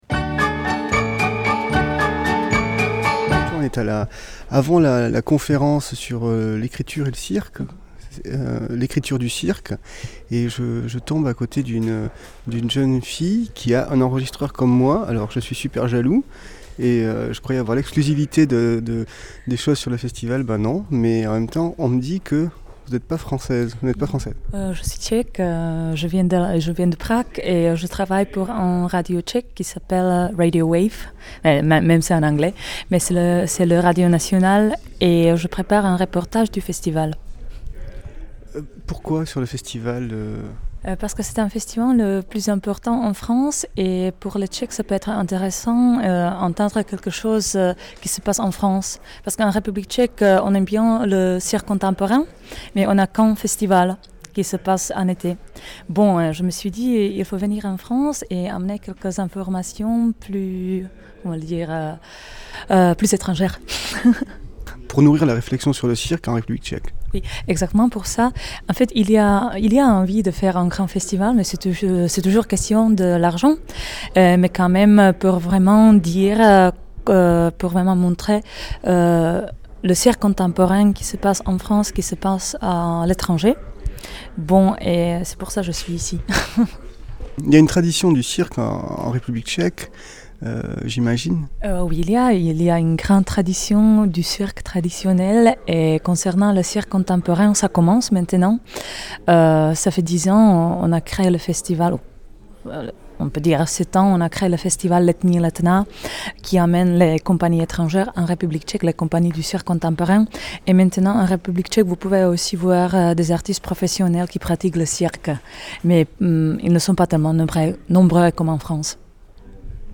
Une journaliste de Radio Wave (radio tchèque) – Ruchemania